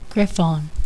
Pronunciation Griff-ON